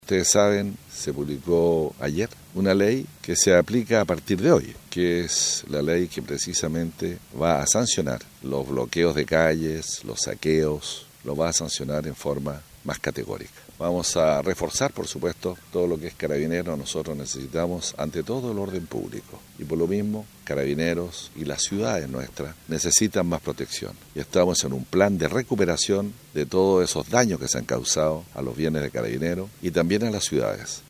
7 detenidos, saqueos al municipio local y comercio, fue el saldo de manifestaciones en Puerto Montt El Intendente de Los Lagos, Harry Jûrgensen enfatizó en la entrada en vigencia de la denominada ley antisaqueos y el reforzamiento de carabineros para el resguardo de la ciudadanía.